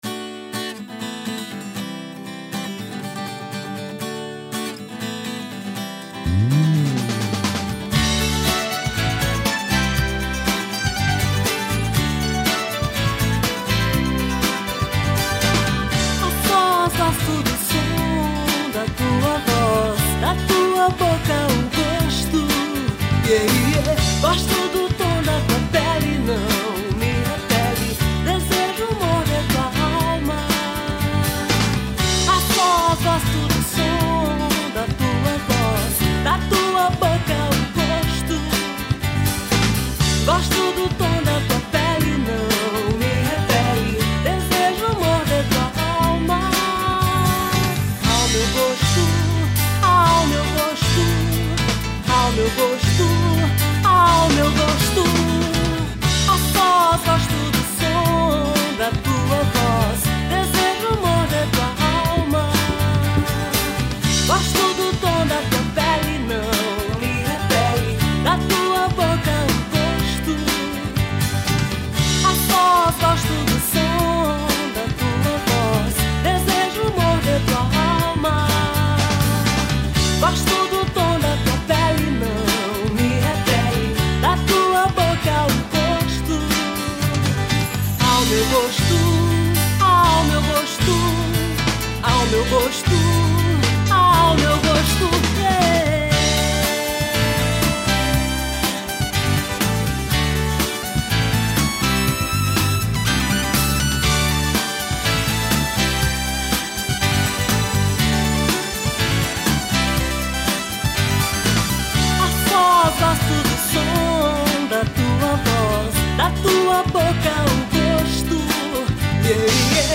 1708   02:54:00   Faixa:     Rock Nacional